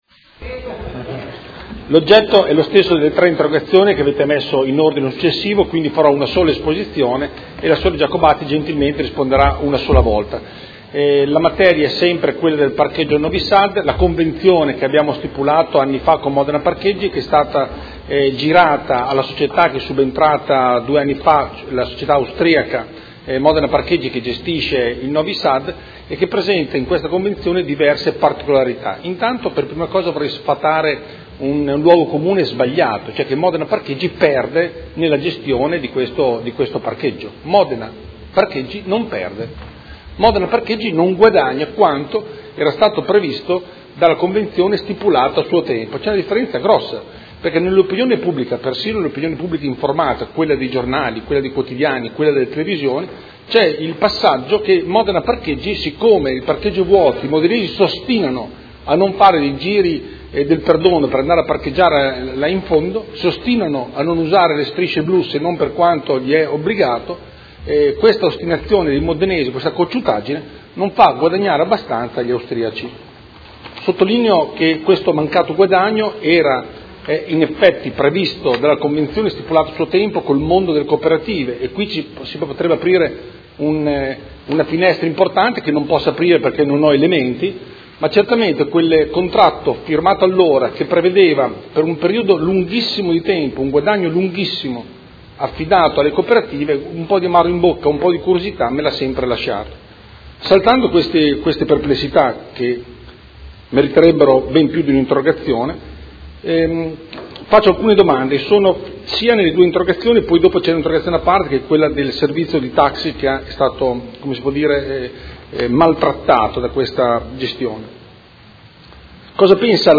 Andrea Galli — Sito Audio Consiglio Comunale
Seduta del 2/2/2017. Interrogazione del Consigliere Galli (F.I.) avente per oggetto: Convenzione Novi Sad: le modifiche alla Convenzione con Modena Parcheggi NON SONO legate a perdite di Bilancio Interrogazione del Consigliere Galli (F.I.) avente per oggetto: BASTA!